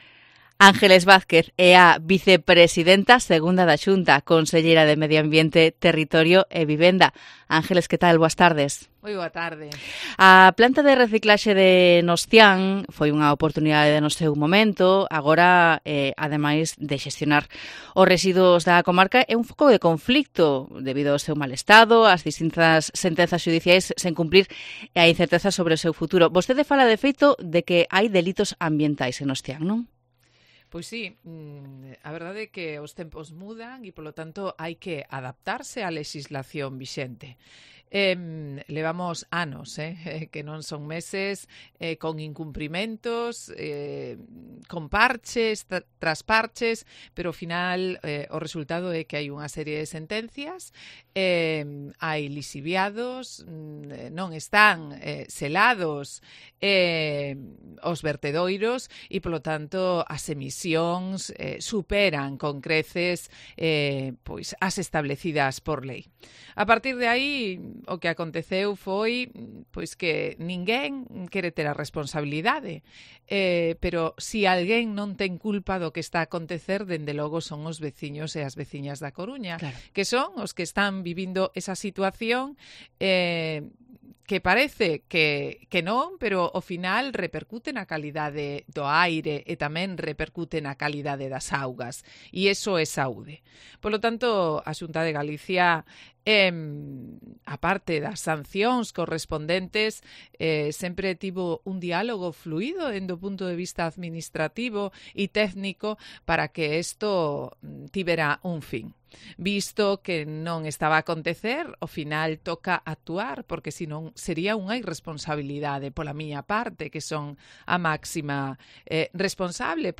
Entrevista con Ángeles Vázquez, vicepresidenta segunda de la Xunta